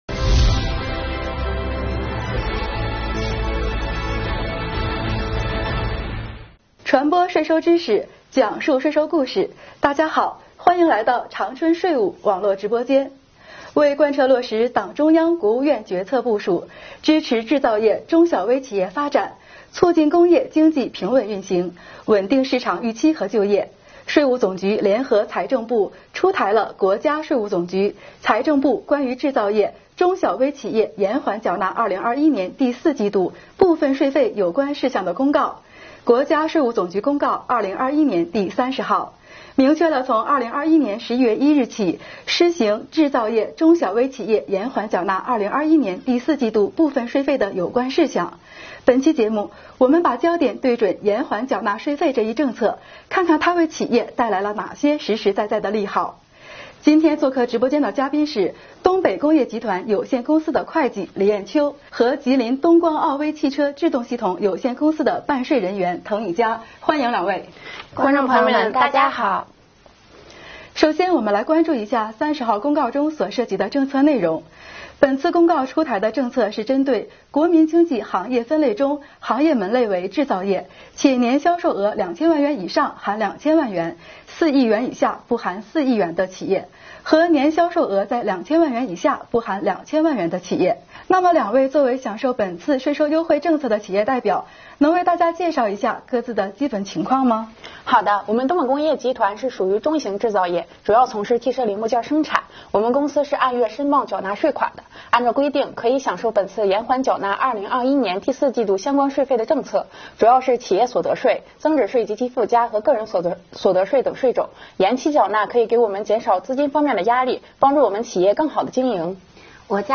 2021年第33期直播回放：制造业中小微企业延缓缴纳2021年第四季度部分税费宣传座谈